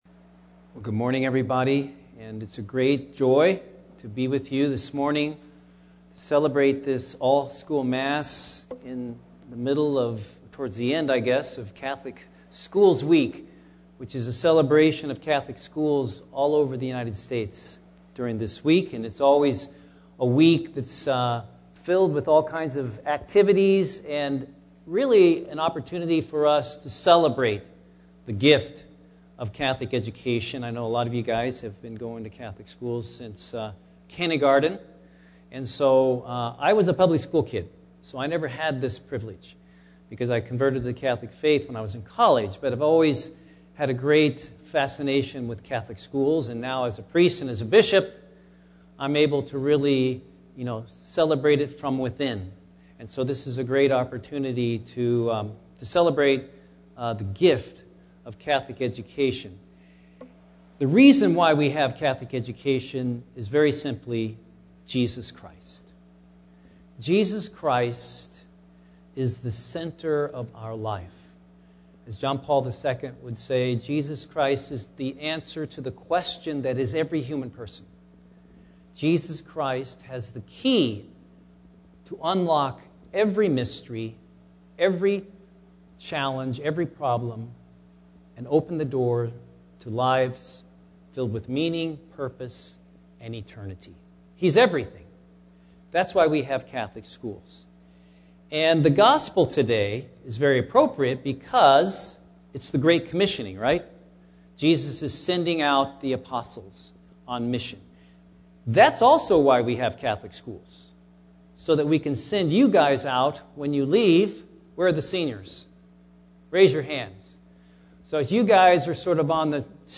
For our All School Mass during Catholic School’s week, Bishop Conley celebrated the Mass and gave a homily for students.
Bishop Conley Homily for Catholic Schools Week
bishop-conley-homily-for-catholic-schools-week.mp3